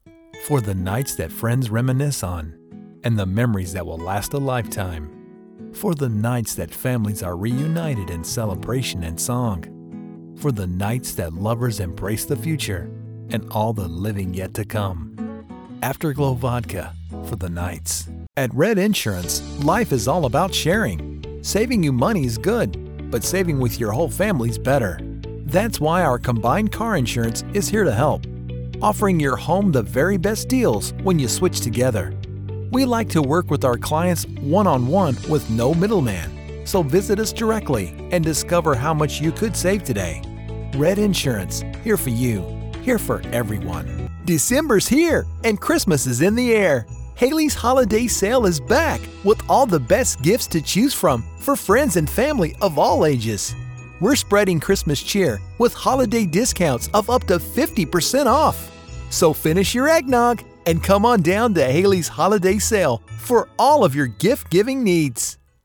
I can deliver a wide range of styles, from friendly and conversational to authoritative and dramatic.
Commercial Demo Reel.mp3